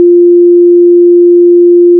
Fa.wav